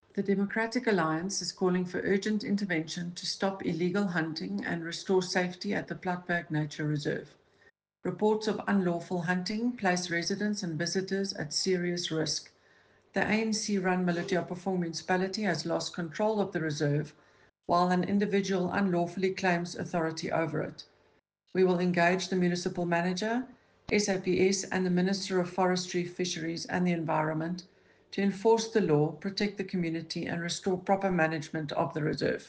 Afrikaans soundbites by Cllr Eleanor Quinta and